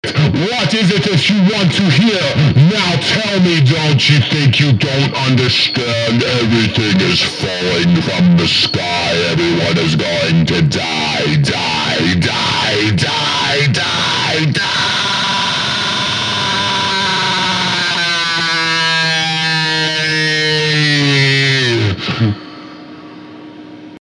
描述：我的扭曲的肥皂盒说教
Tag: 120 bpm Spoken Word Loops Vocal Loops 2.01 MB wav Key : Unknown